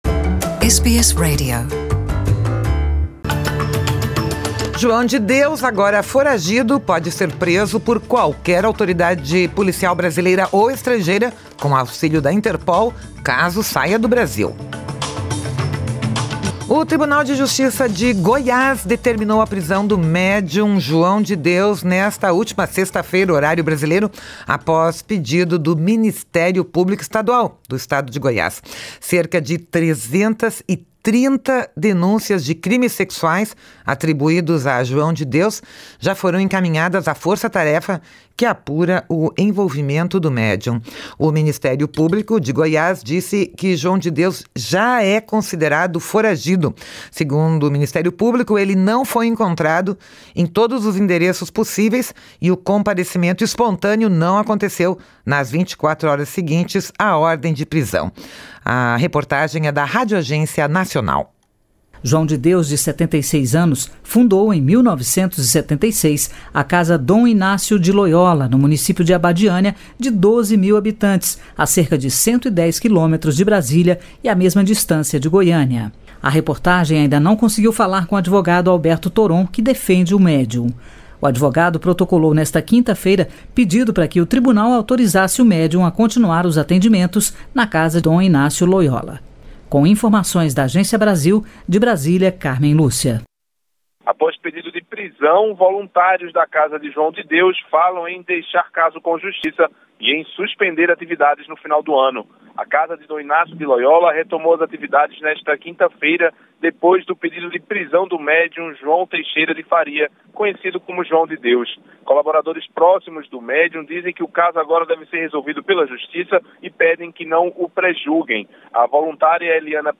Ouça aqui o podcast com a reportagem da Radioagência Nacional.